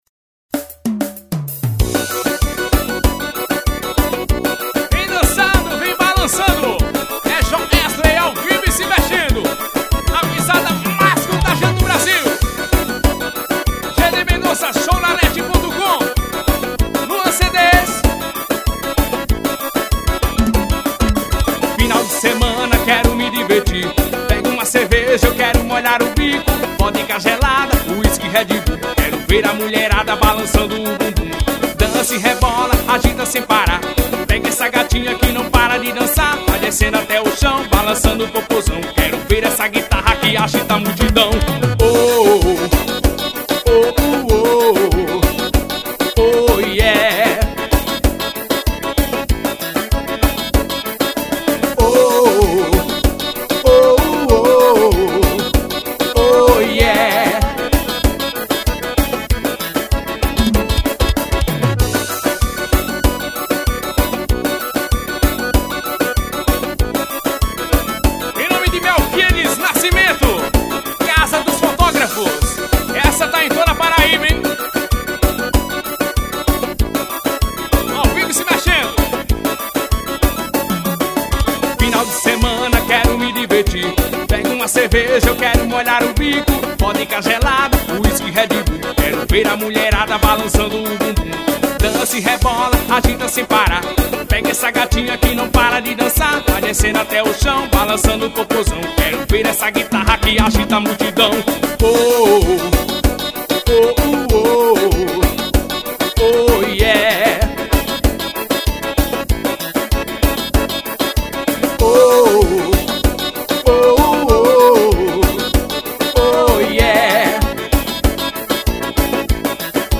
ao vivo e se mexendo